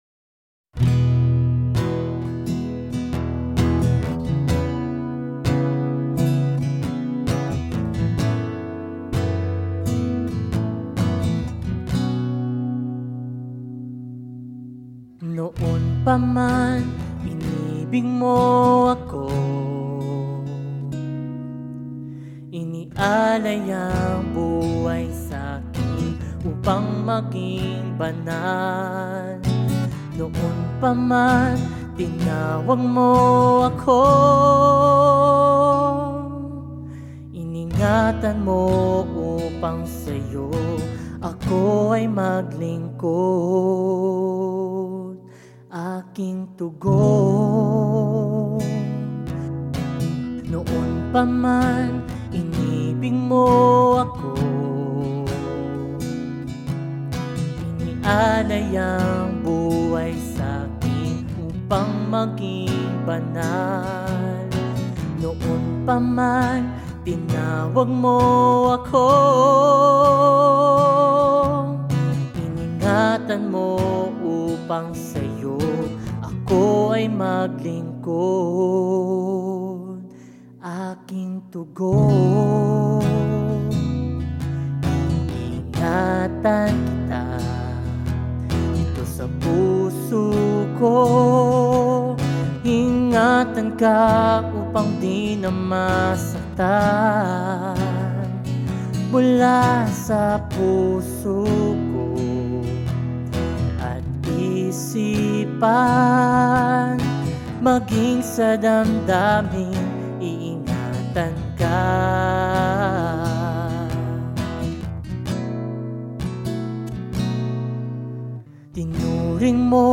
48 просмотров 58 прослушиваний 0 скачиваний BPM: 130